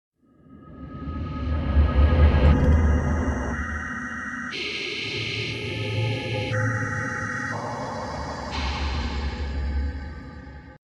Download Cave sound effect for free.
Cave